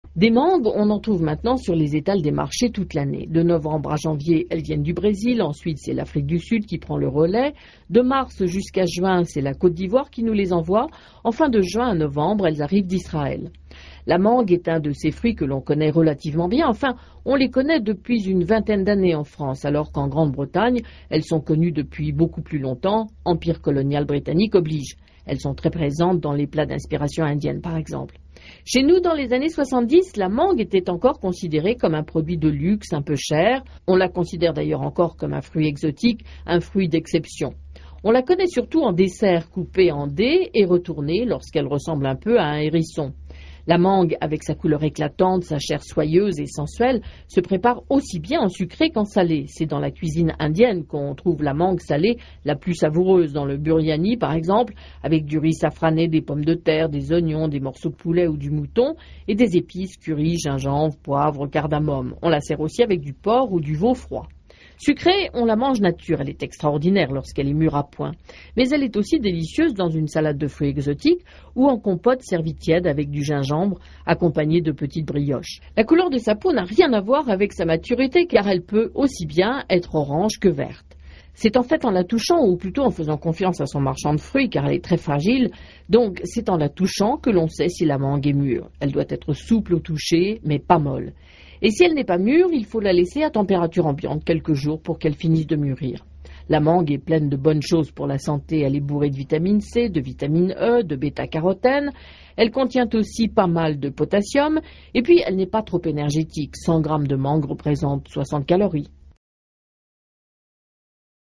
Sur le Marché de Tampon (Reunion)